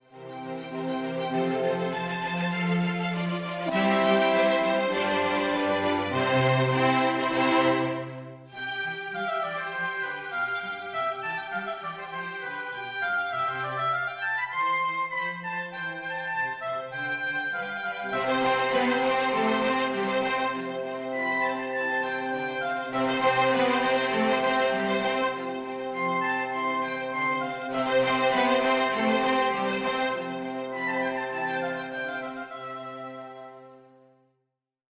Oboe with 2 vns, va, basso and 2 hns.